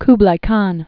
(kblī kän) also Ku·bla Khan (-blə) 1215-1294.